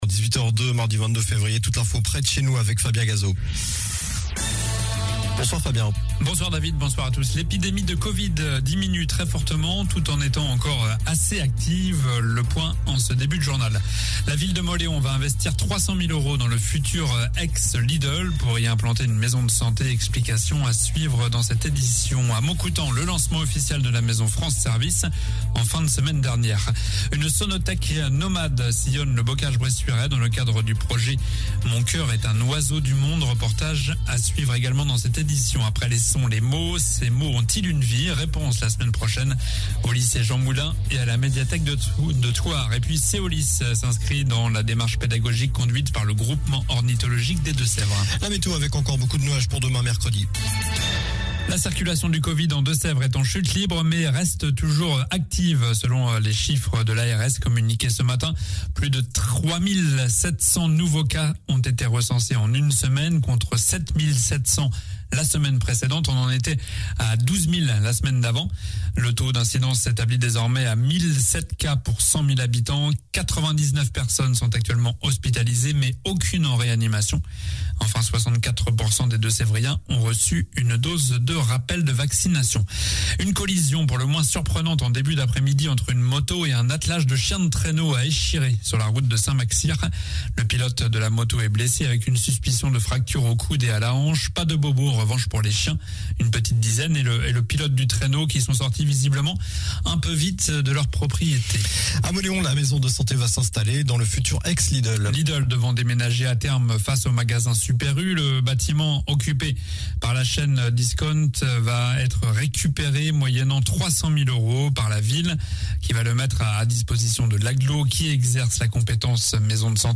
Journal du mardi 22 février soir